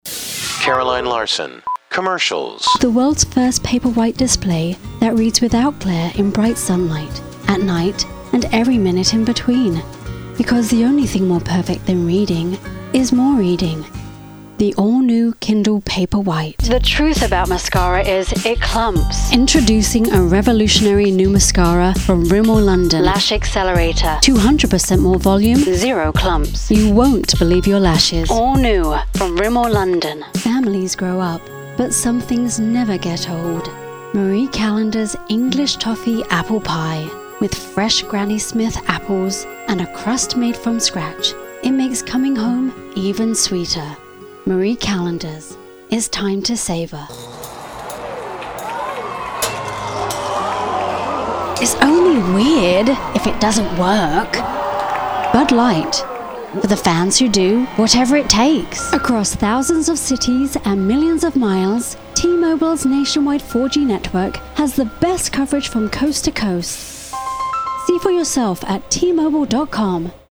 Beautiful, elegant, flowing.
Commercial:
British English
Cockney, Received Pronunciation, Queen’s English